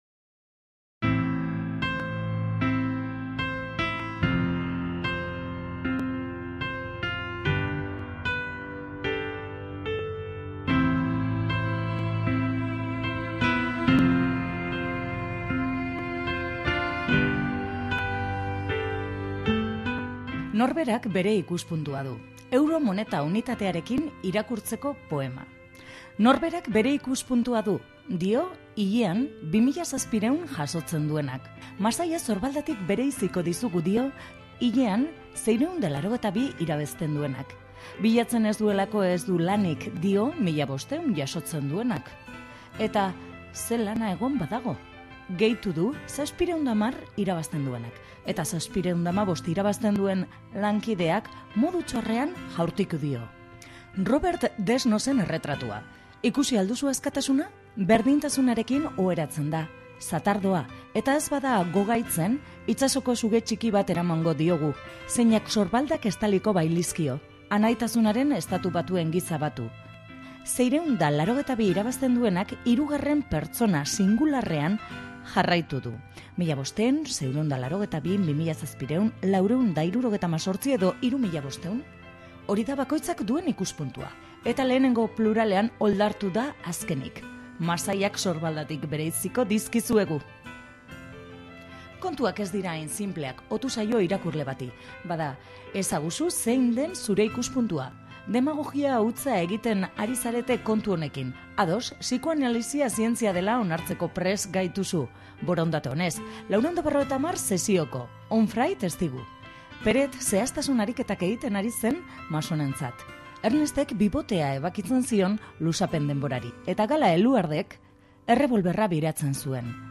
Laugarren lana aurkezten dabiltza egun hauetan, ‘A ala B’ deiturikoa. Laugarren lana da eta oraingoan bisita egin digute, luze hitz egin dugu musikaz eta euren lan berriaz.